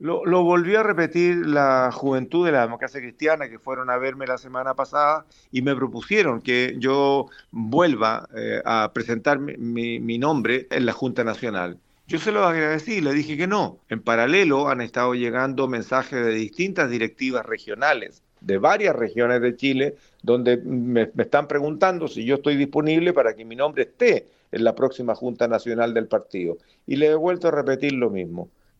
Entre los nombres que se barajan por los militantes está el senador por Los Ríos, Iván Flores, pero en conversación con Radio Bío Bío, Flores otra vez descartó ser el abanderado presidencial pese a las peticiones que habría por las juventudes del partido y de directivas regionales.